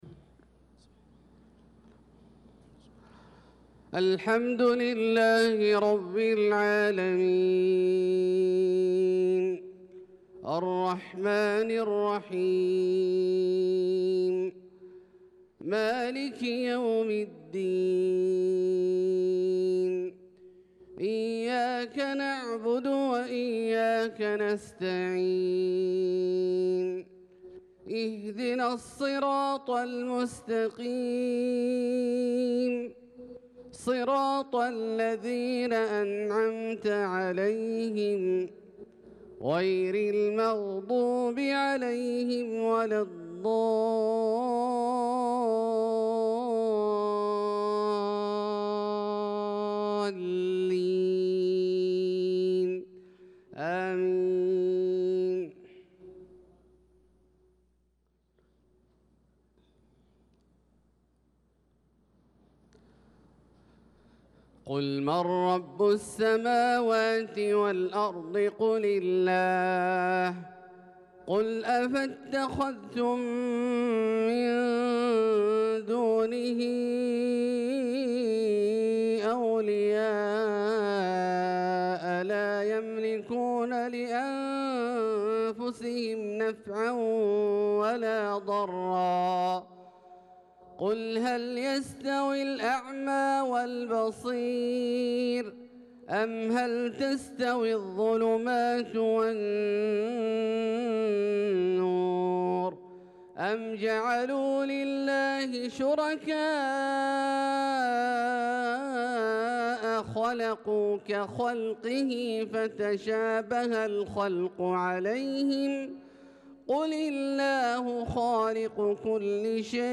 صلاة الفجر للقارئ عبدالله الجهني 27 ذو الحجة 1445 هـ